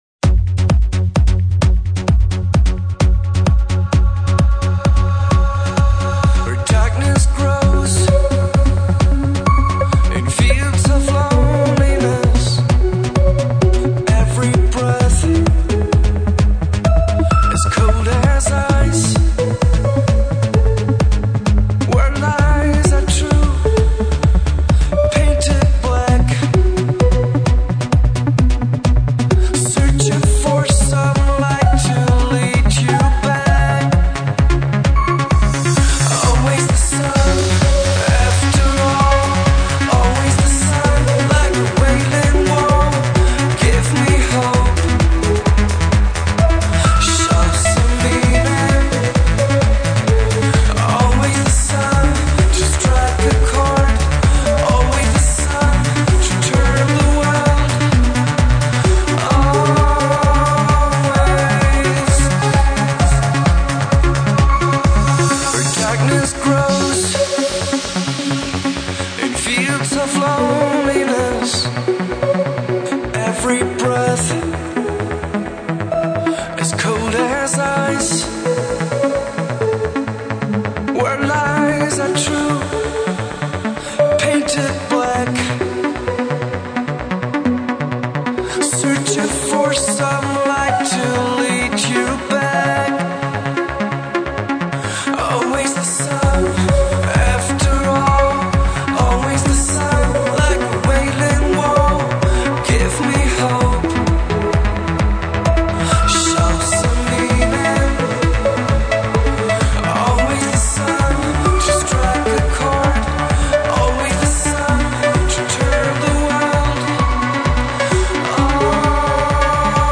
Категория: Pop